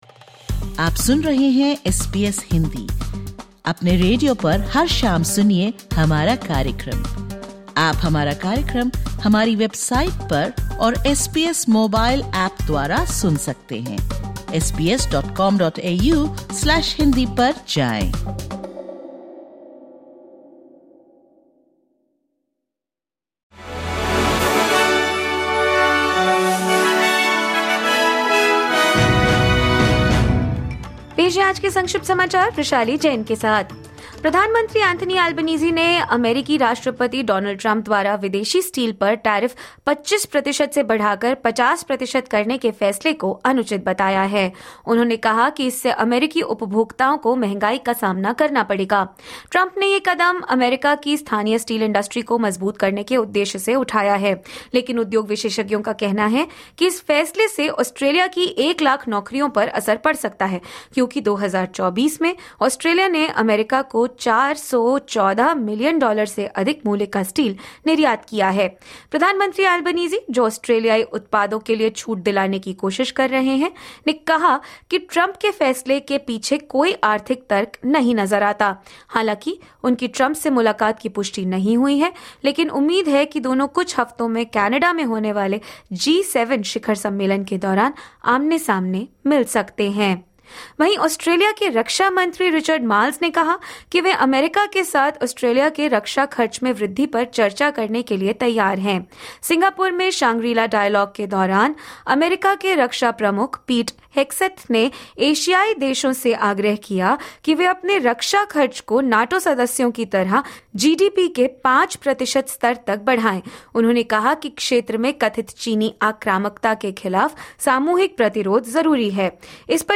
Listen to the top News of 01/06/2025 from Australia in Hindi.